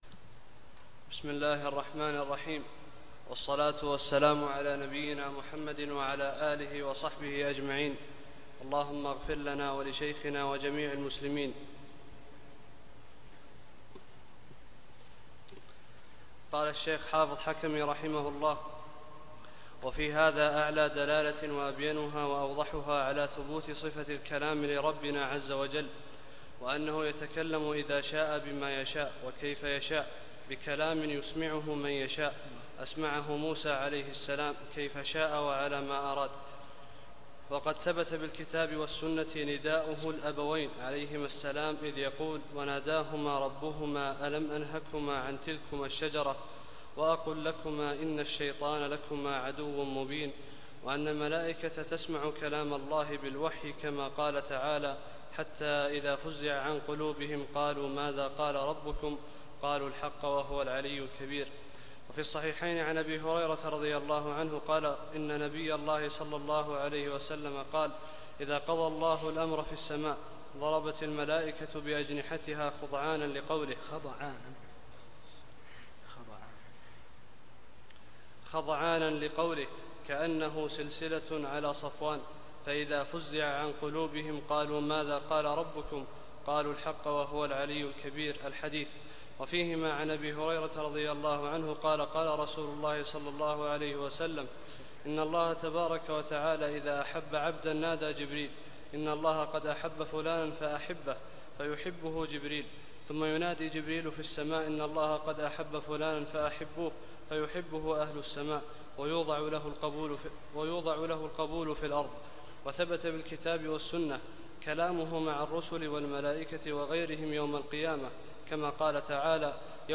34 - الدرس الرابع والثلاثون